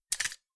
Index of /server/sound/weapons/hk45